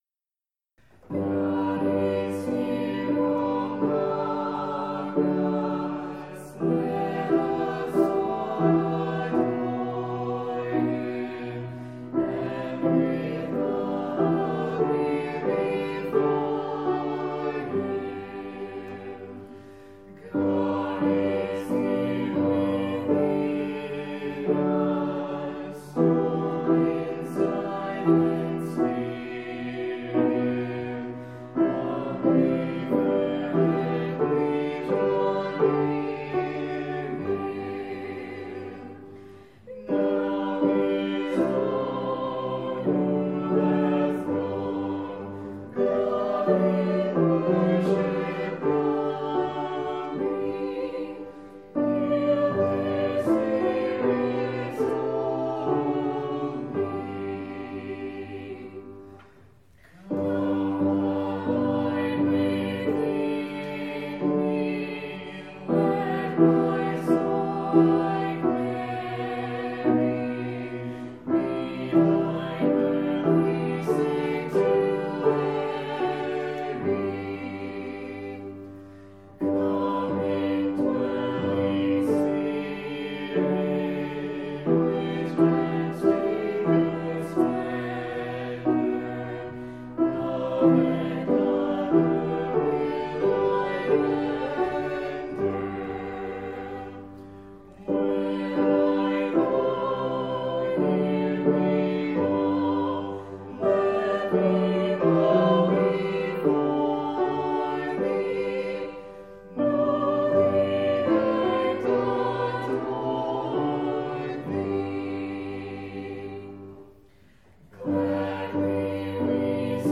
Hymns:
Soprano
Alto
Tenor
Bass
Piano
5.24.20-Hymns.mp3